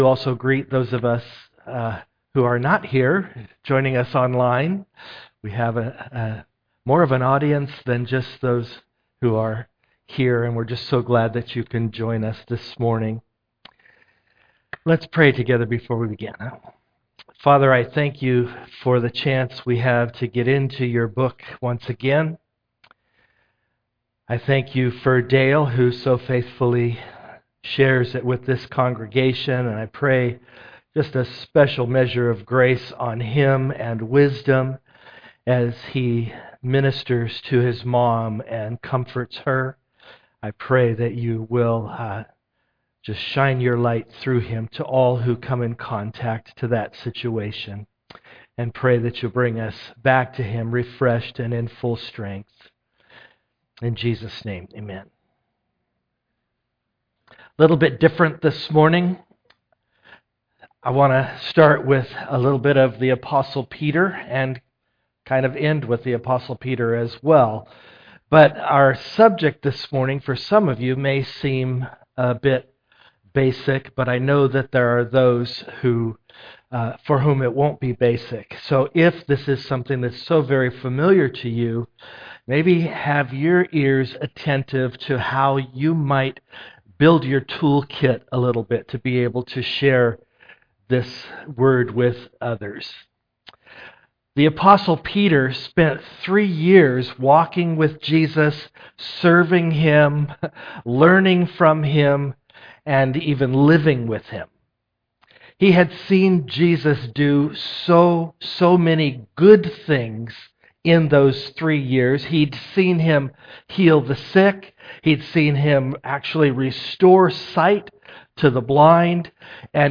1 Peter 3:18 Service Type: am worship Download Files Notes « Avoiding Recipes for Disaster Thriving With Joseph